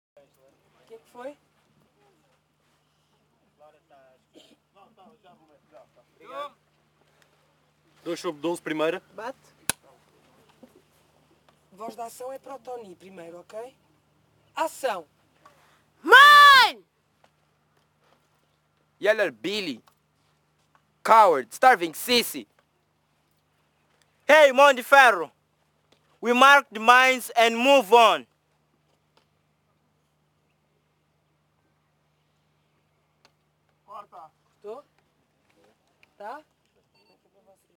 Une grande journée d’effets spéciaux nous attend sur la piste de Chiango que nous prenons pour la dernière fois et qui mène au décor du champ de mines de la séquence 2 du film.
La République des enfants – 2 / 12 t1 – perche seule au centre